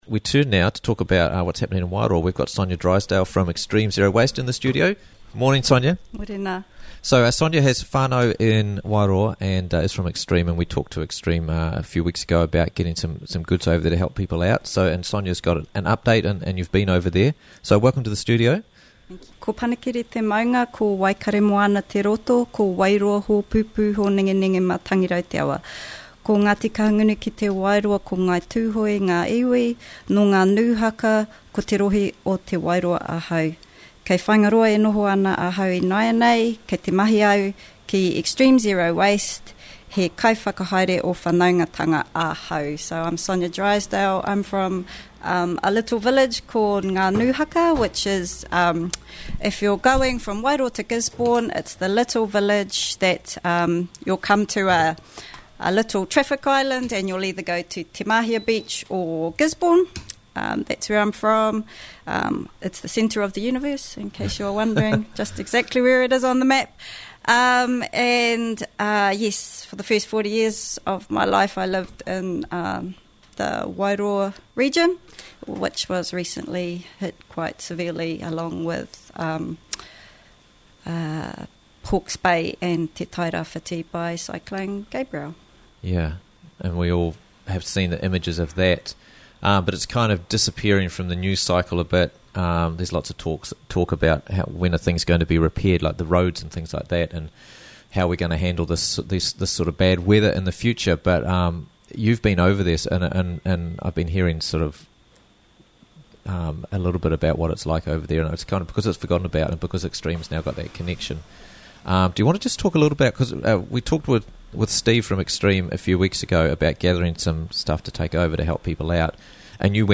Visit to Wairoa - Interviews from the Raglan Morning Show